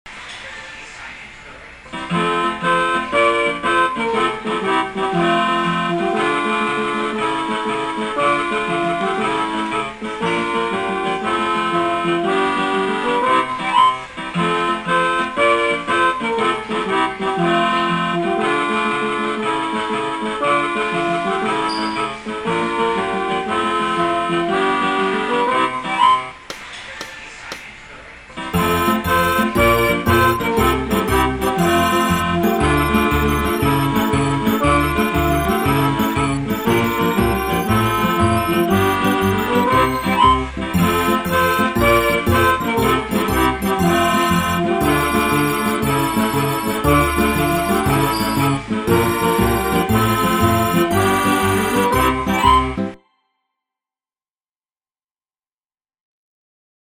The accordion part, too, sounded just like mine.